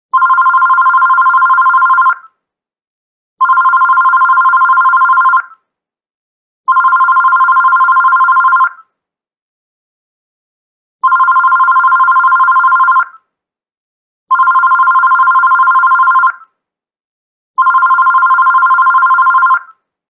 Categories Electronic Ringtones